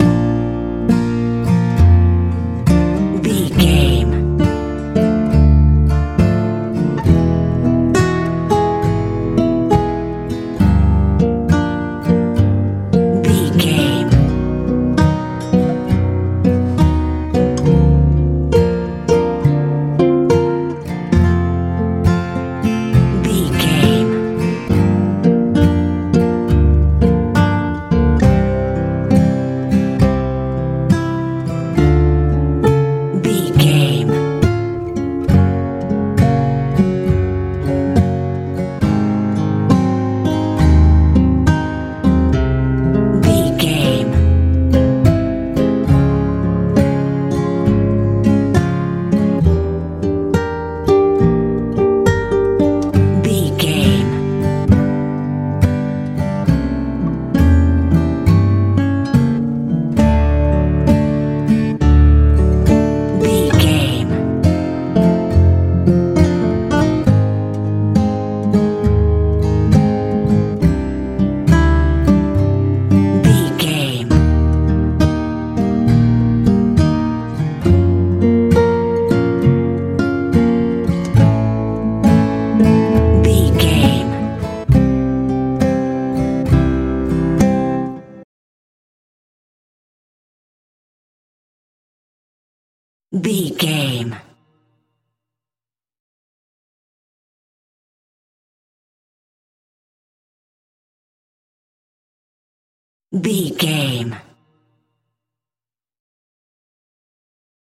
campfire feel
Ionian/Major
light
mellow
acoustic guitar
calm